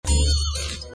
Anime Metal Boing Sound Effect Sound Buttons